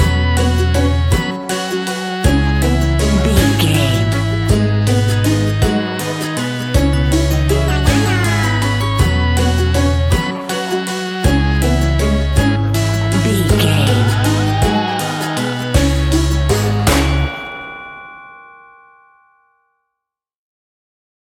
Aeolian/Minor
C#
scary
ominous
dark
eerie
acoustic guitar
percussion
strings
spooky